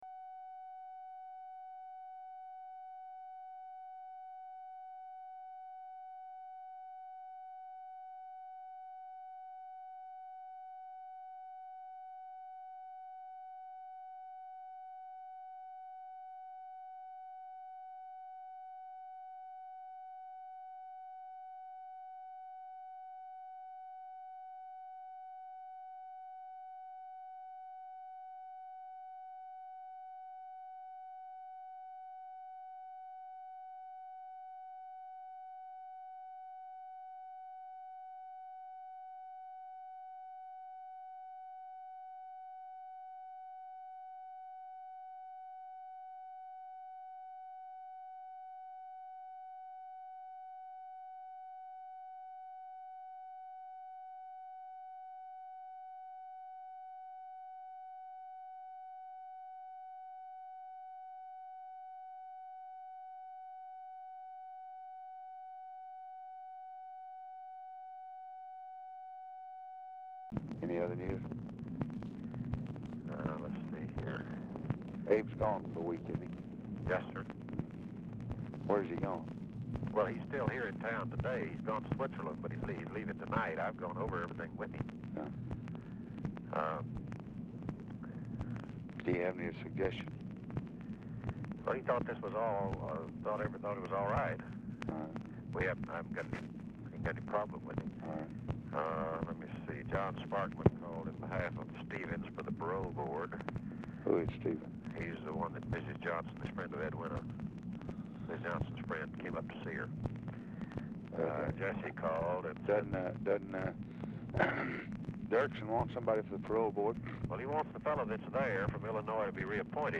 Telephone conversation # 3929, sound recording, LBJ and WALTER JENKINS, 6/26/1964, 5:30PM | Discover LBJ
Format Dictation belt
Location Of Speaker 1 Detroit, Michigan